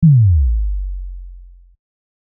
combobreak.wav